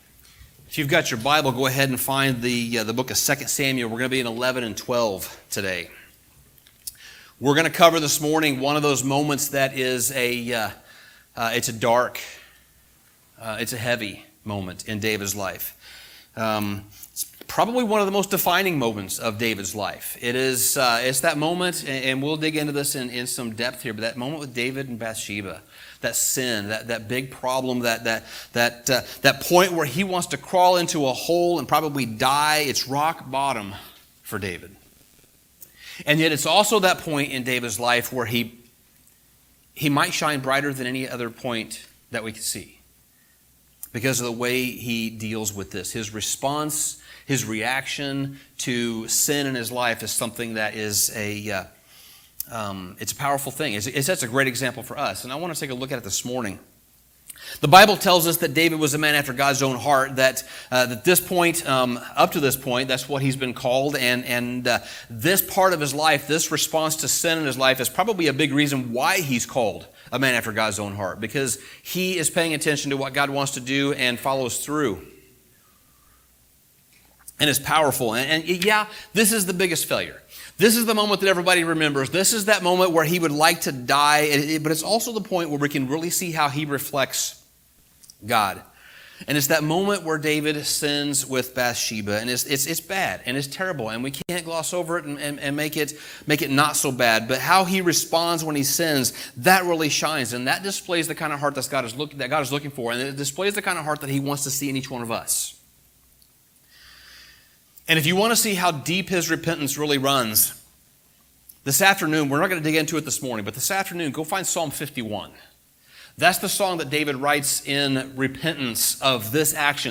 Sermon Summary David was called a man after God's own heart.